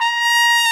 BRS TRMPF0KL.wav